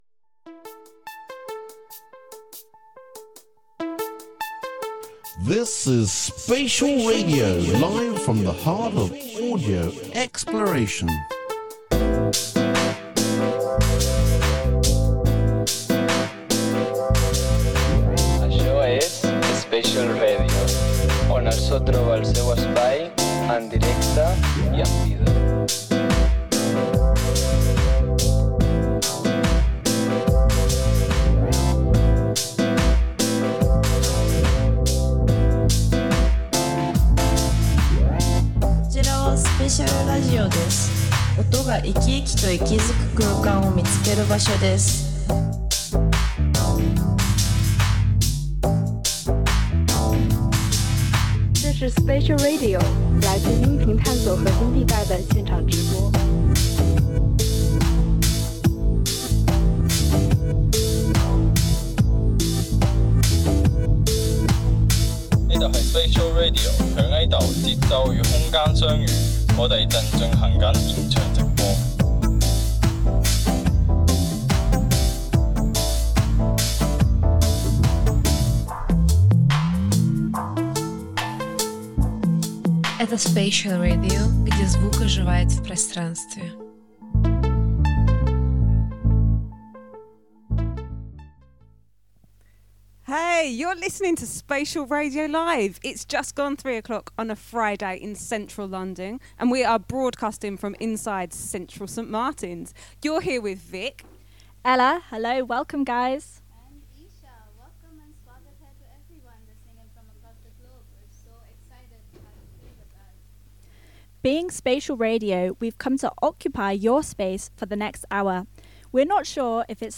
Spatial Radio Menu Home Archive Upcoming 00m 00s 00m 00s Mute We are Spatial Radio A learning platform using radio and sound to explore and intervene in space (Close) Volume MA Cities x MA Narrative Environments Presents: Spatial Radio Live at Central Saint Martins -- bring yesterday’s weather to life through sound, story, and space.
Segments: Archival history / Ambient Soundscapes / Curatorial Reflections / Factual and Poetic Weather Reports / Speculative Forecasts / Music Interludes / Community Interviews